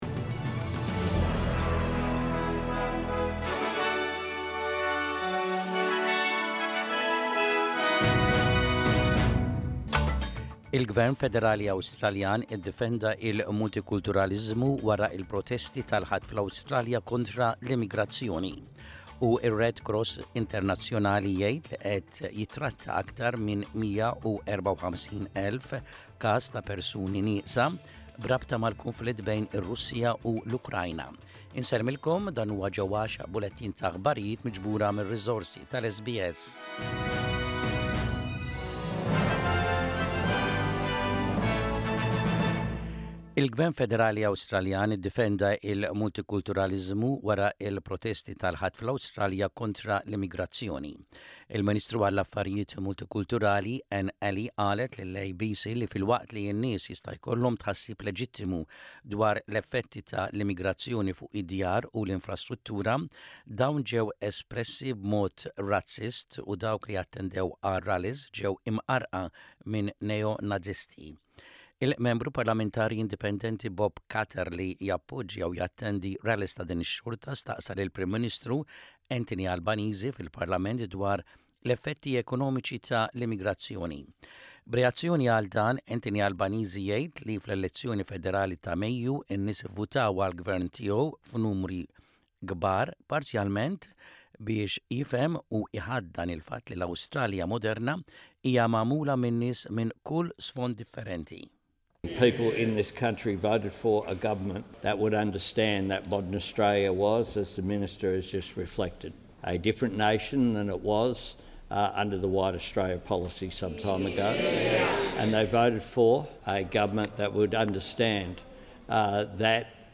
SBS Maltese News: 02.09.25